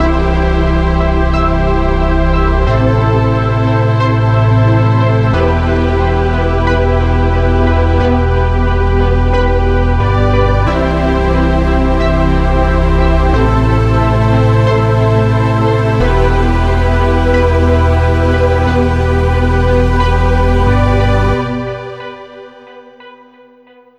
300+ Serum 2 presets built for producers who want massive, cinematic sound — whether you’re working on rock, metal, electronic, or film scores.
Foundation - Core Sounds
From deep basses and lush pads to versatile leads, keys, and plucks — these sounds form the structural core of any professional production.
Demo-Foundation.mp3